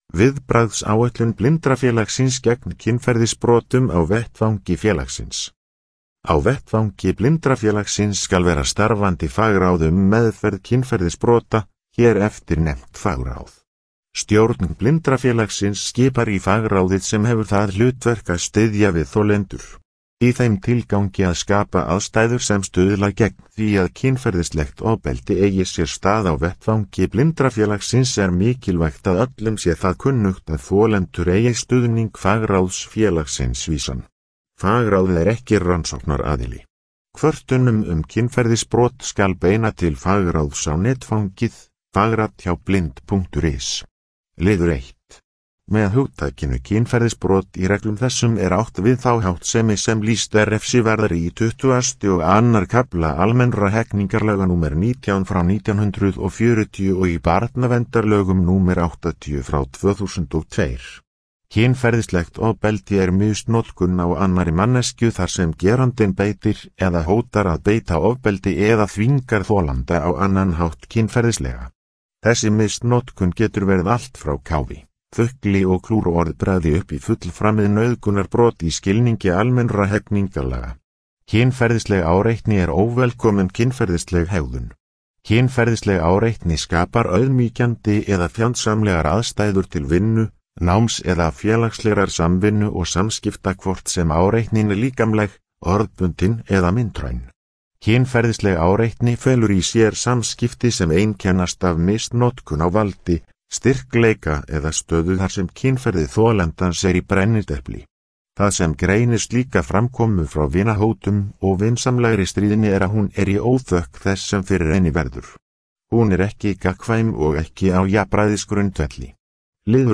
Upplesið